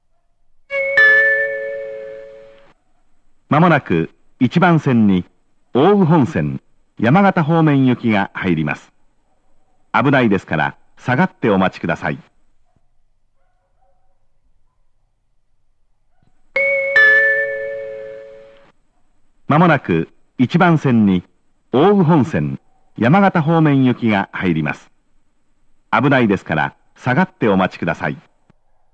山形方面接近放送
●上りが男声、下りが女声の、路線名まで言ってくれる細かいアナウンスです。
●接近チャイムは仙台駅仙石線と同じタイプ。
●スピーカー：ユニペックス小型